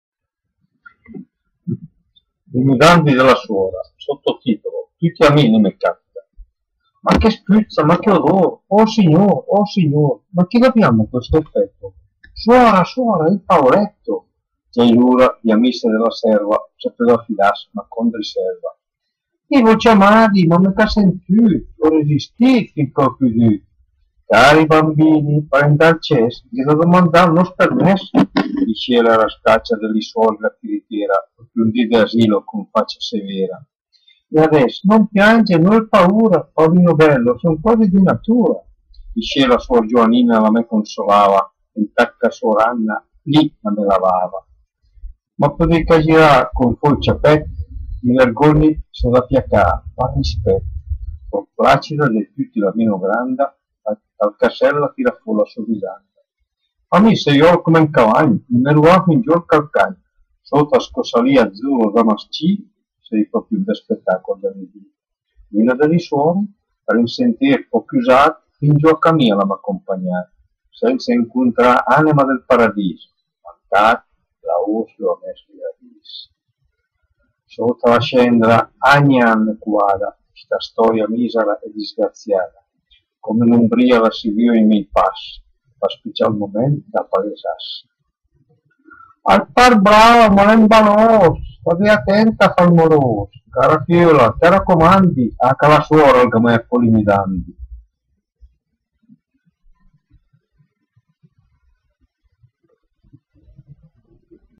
Poesia in dialetto di Albosaggia